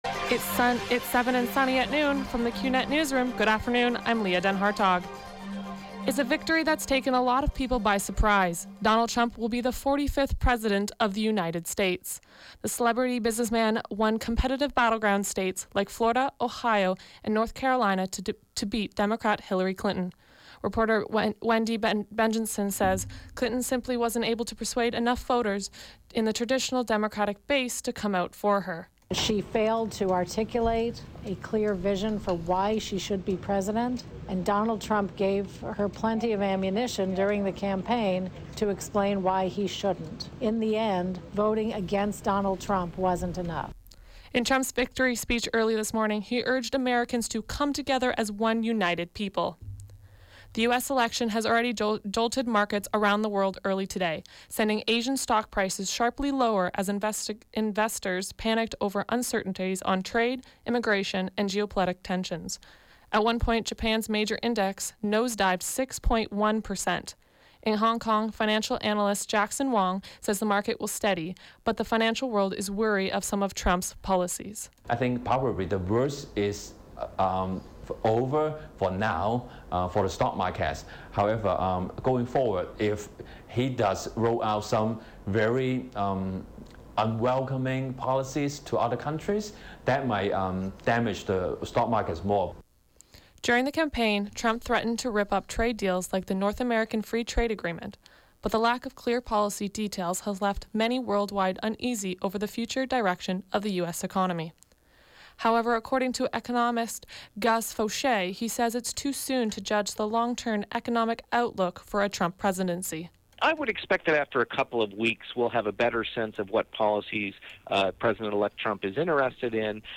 91X FM Newscast – Wednesday, Nov. 9, 2016, noon